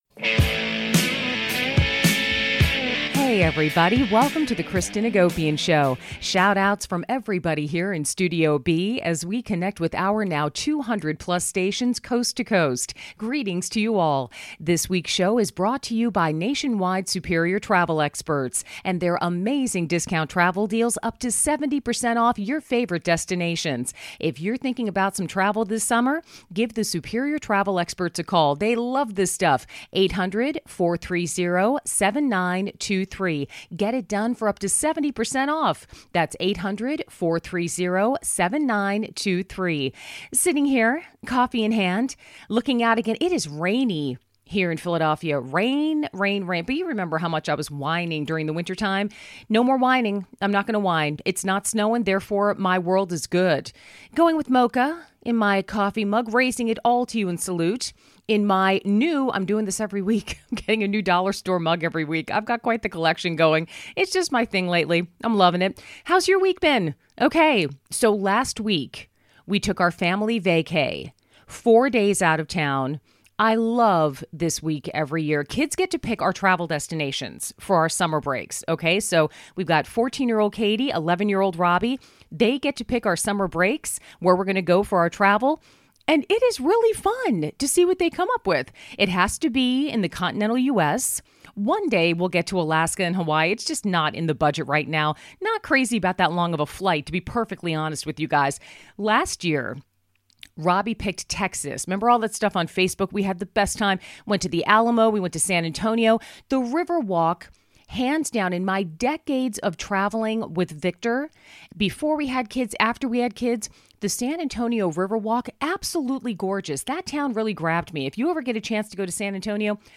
guest John Stamos